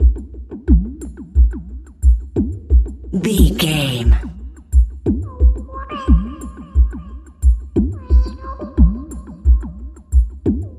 Electronic loops, drums loops, synth loops.,
Epic / Action
Fast paced
In-crescendo
Ionian/Major
Fast
aggressive
industrial
driving
energetic
hypnotic
mechanical